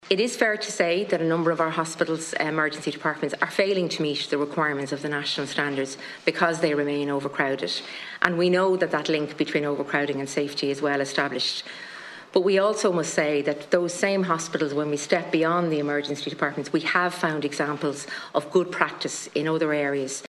HIQA Chief Executive, Angela Fitzgerald, says some EDs are making improvements, but others are falling short……..